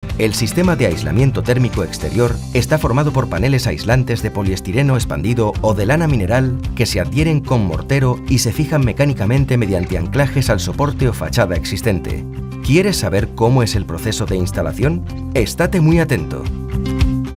Comercial, Natural, Versátil, Seguro, Empresarial
E-learning